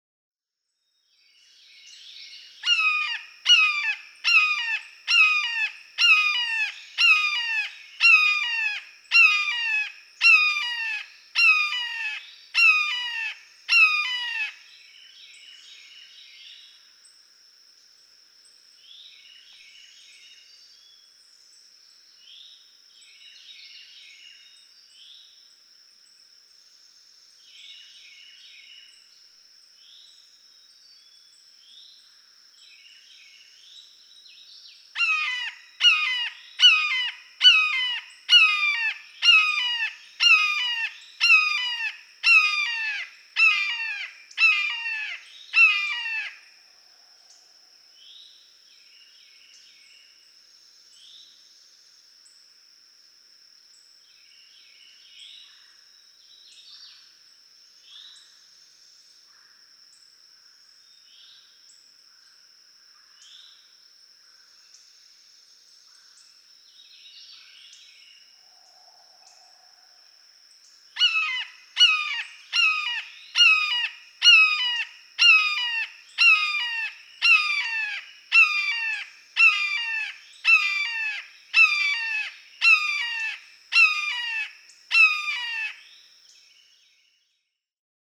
Red-shouldered hawk
♫36. Three kee-aah calling bursts from a young bird, already sounding very much like an adult.
Shawnee National Forest, Eddyville, Illinois.
036_Red-shouldered_Hawk.mp3